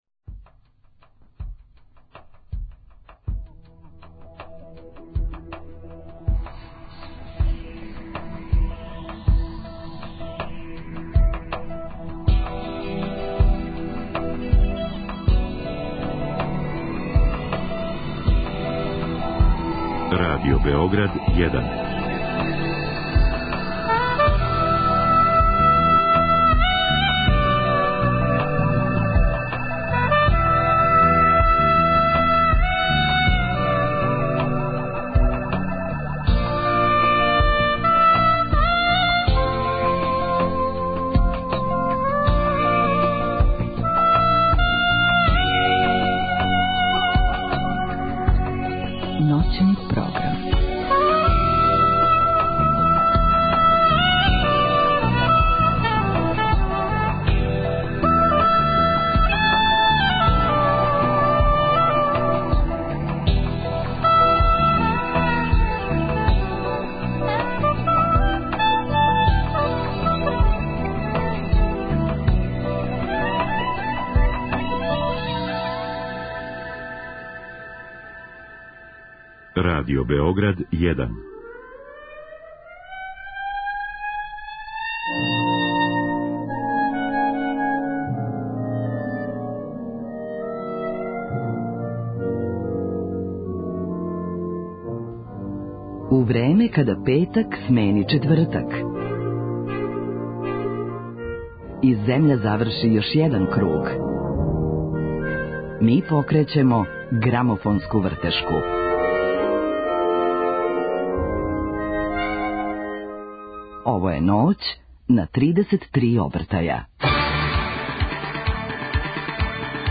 Дружићемо се уз домаћу забавну музику, прецизније, уз шлагере. У последњој четвртини емисије чућемо 5 песама које су на данашњи дан биле на врху Билбордове листе, а представићемо вам и новитет издат на грамофонској плочи.